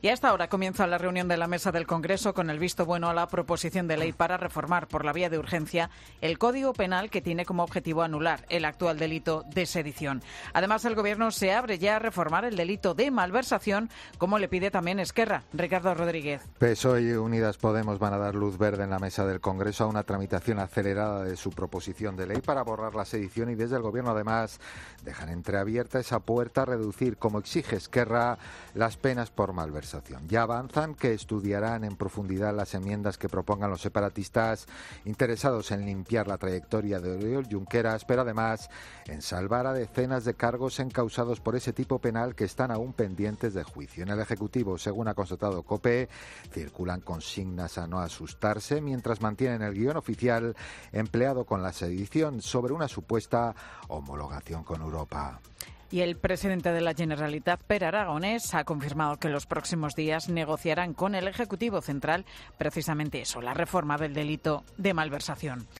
Gobierno y ERC comienzan a negociar la reforma del delito de sedición. Crónica